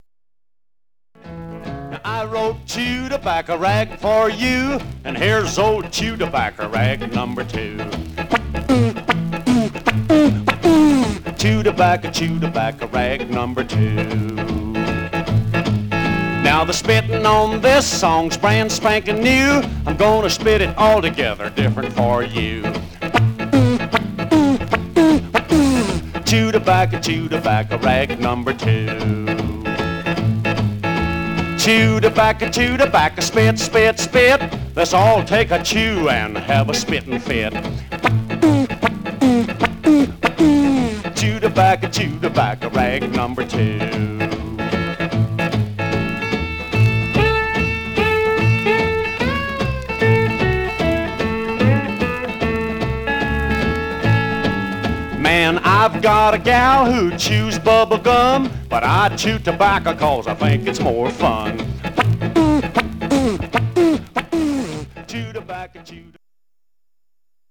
Condition Surface noise/wear Stereo/mono Mono
R&B Instrumental